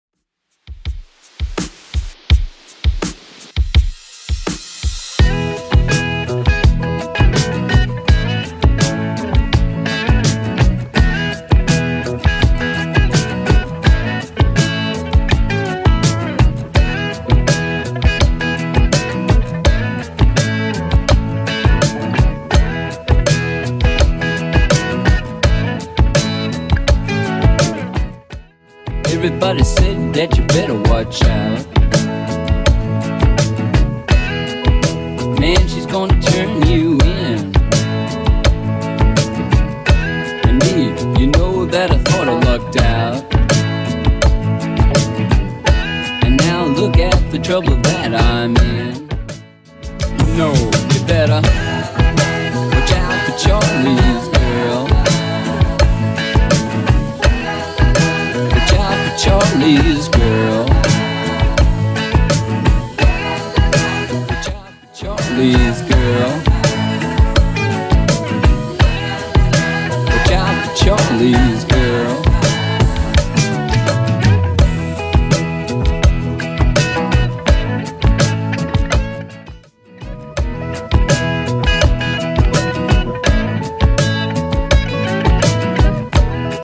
Genre: 90's
BPM: 102